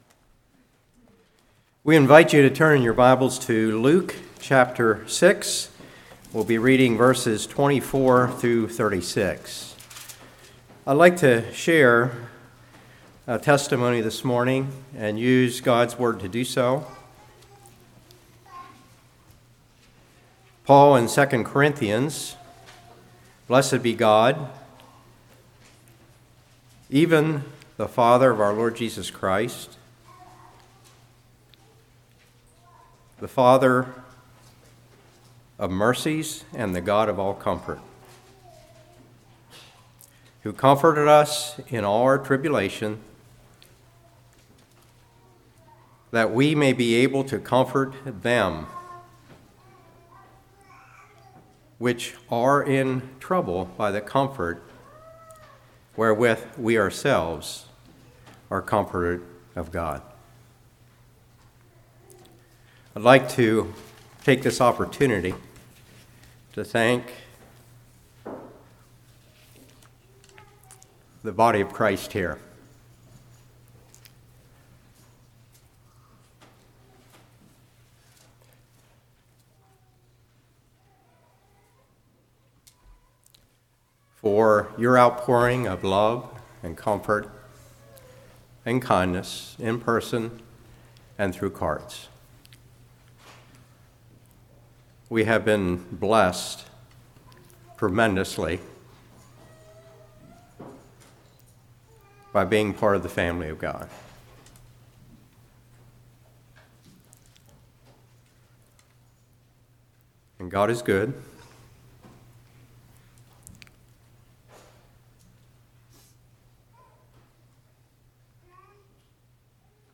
Luke 6:24-36 Service Type: Morning Each Kingdom Has It’s Own Ruler